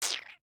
salamander-v2.ogg